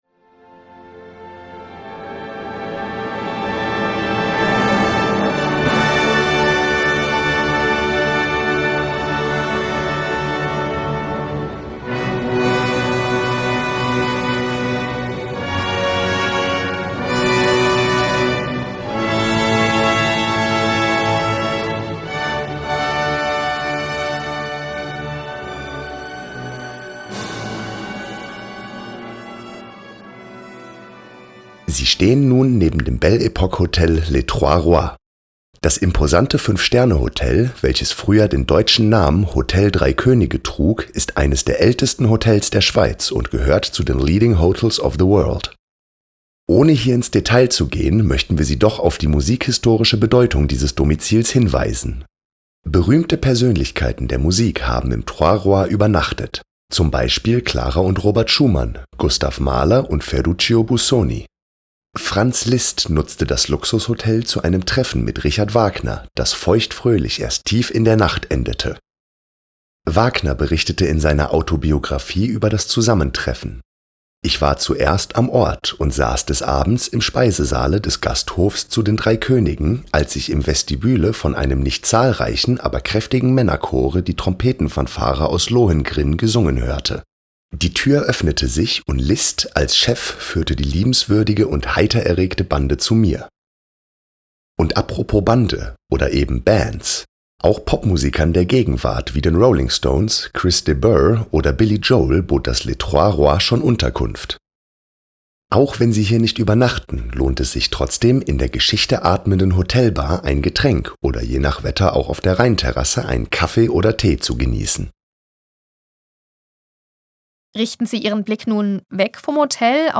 QUELLEN der Musikbeispiele: